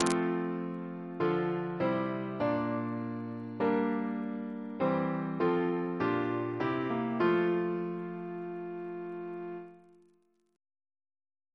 Single chant in F Composer: F. A. Gore Ouseley (1825-1889) Reference psalters: ACB: 269